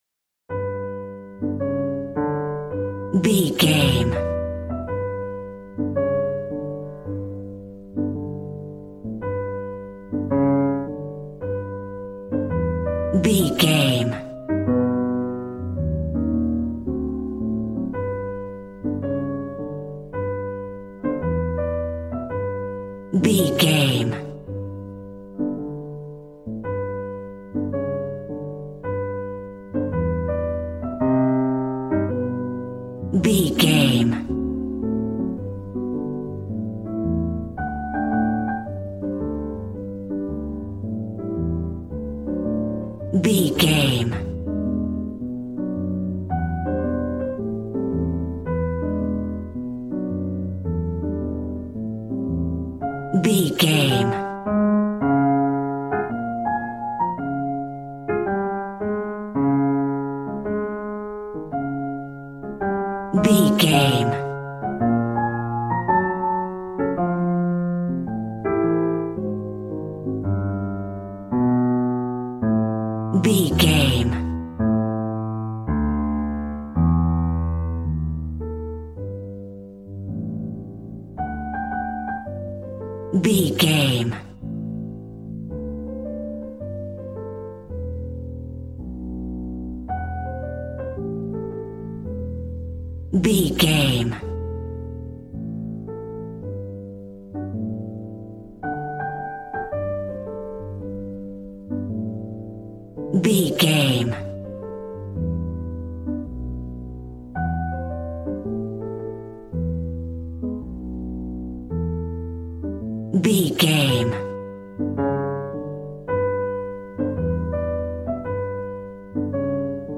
Smooth jazz piano mixed with jazz bass and cool jazz drums.,
Aeolian/Minor
drums